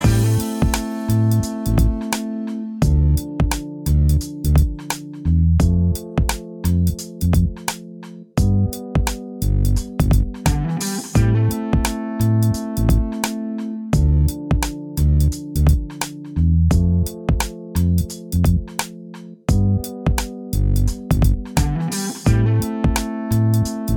Minus All Guitars Pop (1990s) 3:32 Buy £1.50